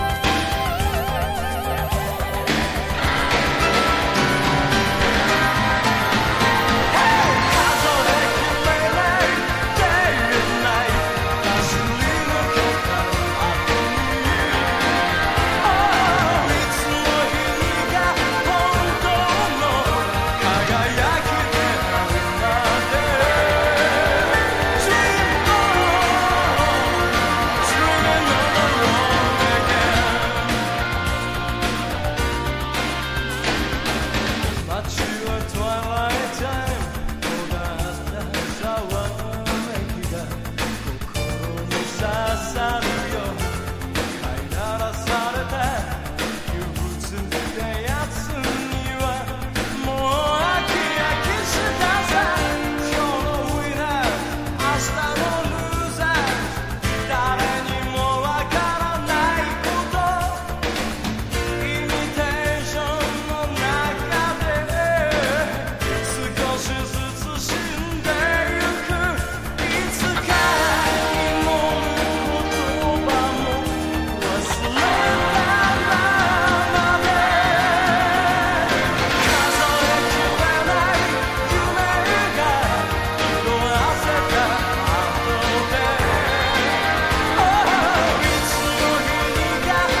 ブリティッシュ・ビートやモータウンを参照したようなストレートなロックナンバーや並ぶ1枚。
60-80’S ROCK